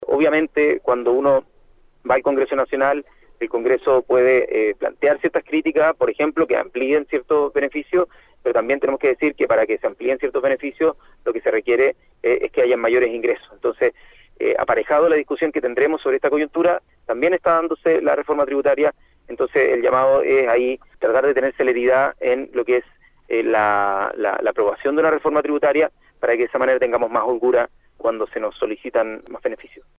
De paso por la Región del Biobío, y en medio de una apretada agenda de actividades, el ministro de Desarrollo Social y Familia, Giorgio Jackson, en entrevista con Radio UdeC, se refirió a varias instancias impulsadas por la administración Boric y que se vinculan a su cartera, entre ellas, el Plan Buen Vivir.